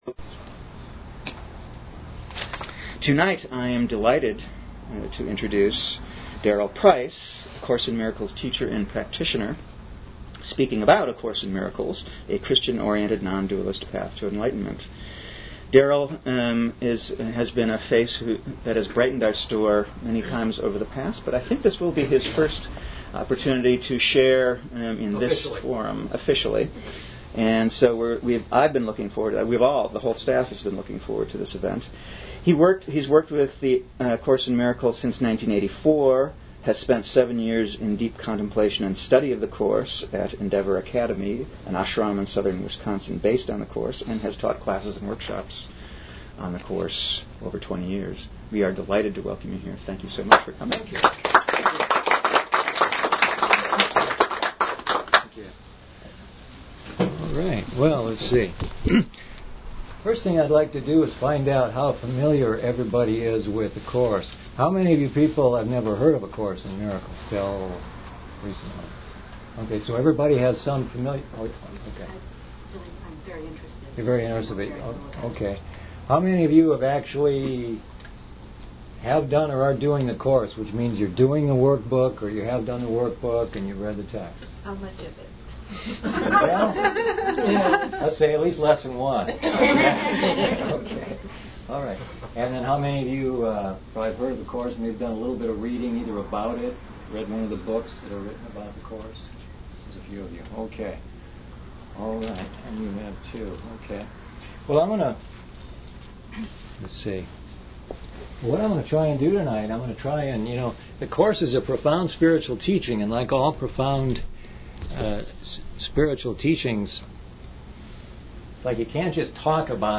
Archive of an event at Sonoma County's largest spiritual bookstore and premium loose leaf tea shop.
Questions are encouraged.